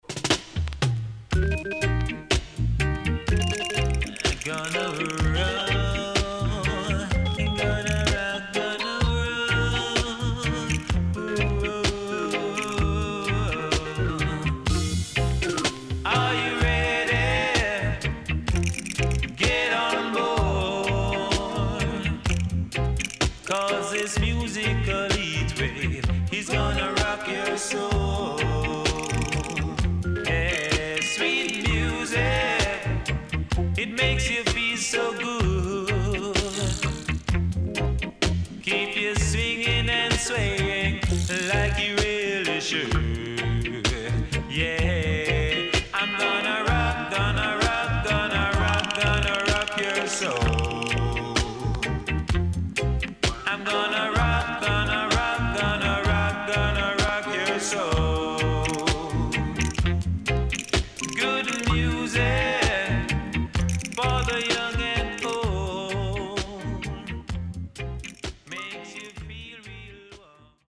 Sweet Vocal
SIDE B:序盤ノイズ入ります。